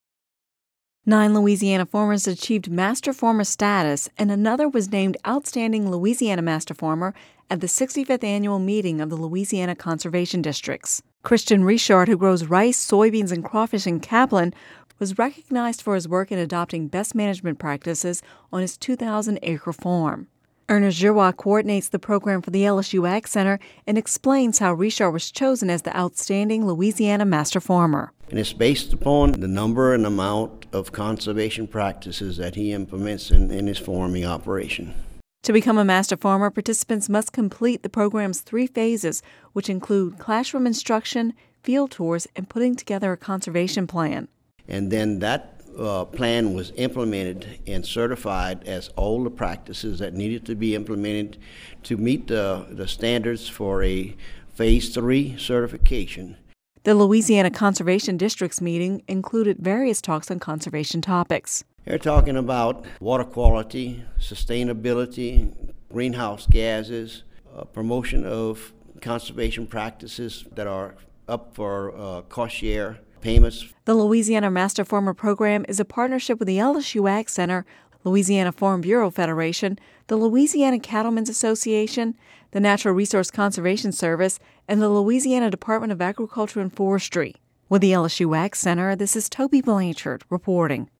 (Radio News 01/31/11) Nine Louisiana farmers achieved Master Farmer status and another was named outstanding Louisiana Master Farmer at the 65th annual meeting of the Louisiana Conservation Districts.